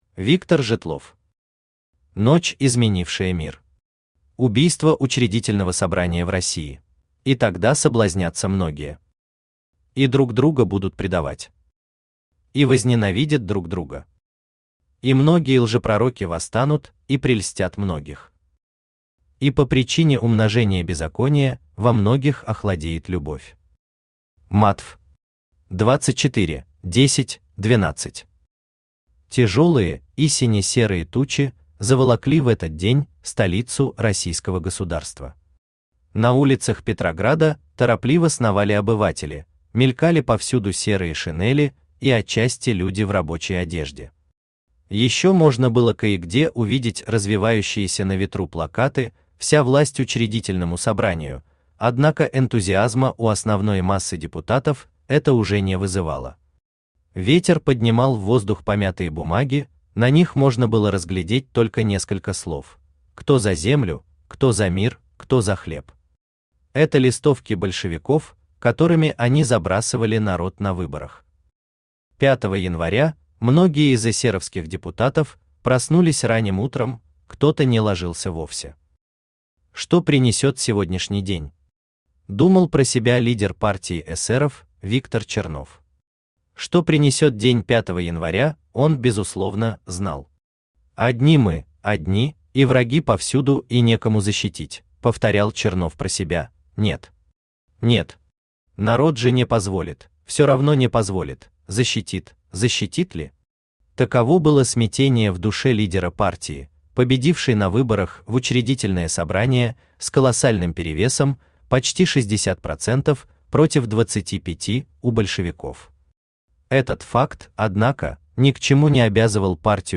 Аудиокнига Ночь изменившая мир. Убийство Учредительного собрания в России | Библиотека аудиокниг
Читает аудиокнигу Авточтец ЛитРес.